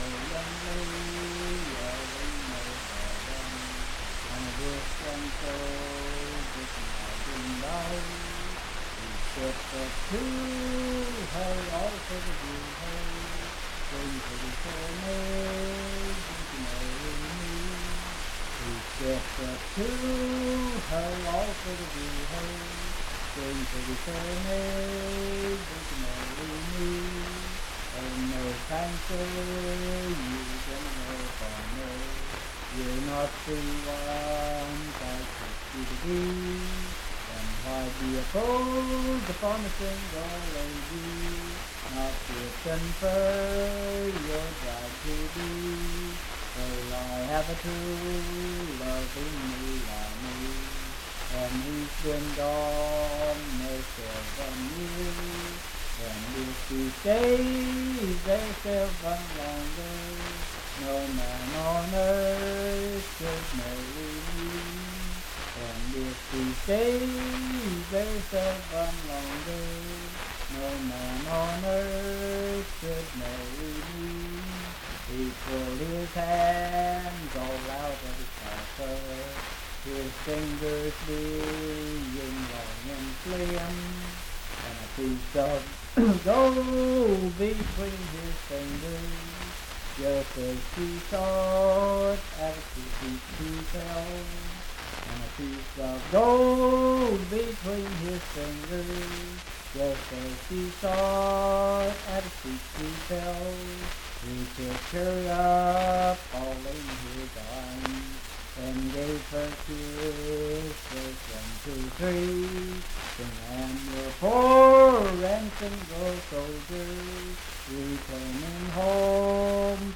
Unaccompanied vocal music
Verse-refrain 6(4-6).
Voice (sung)
Pendleton County (W. Va.)